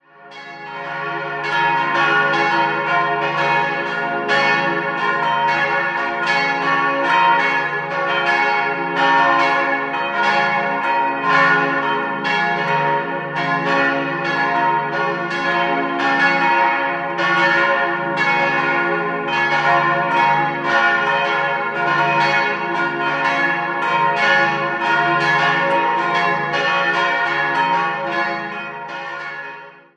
4-stimmiges Salve-Regina-Geläute: des'-f'-as'-b'